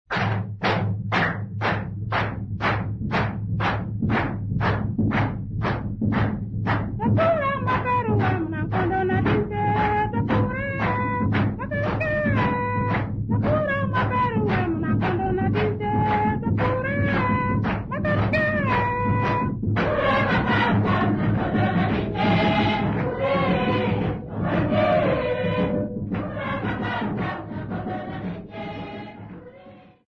Sambiu church music workshop participants
Sacred music Namibia
Ngoma (Drum) music Namibia
Hand-clapping music Namibia
Africa Namibia Sambiu mission, Okavango sx
field recordings
Church song with drum and clapping accompaniment.